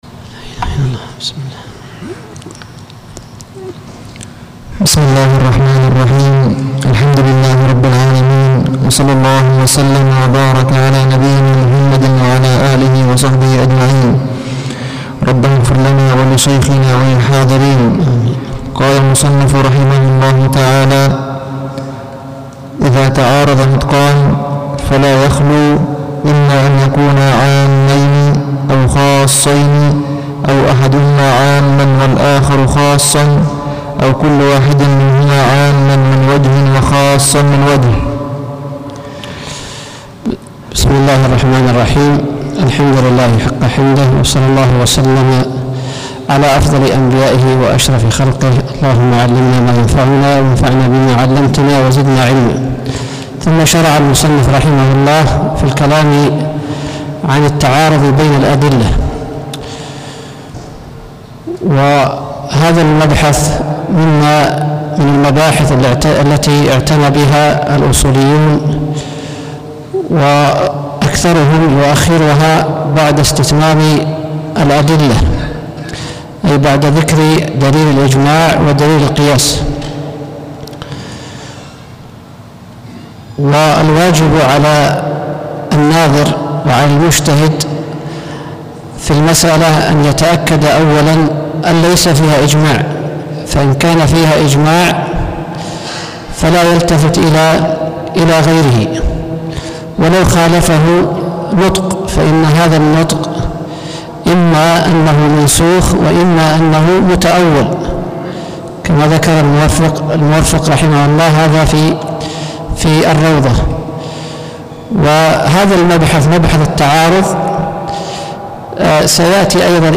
الدرس الخامس عشر: التعارض بين الأدلة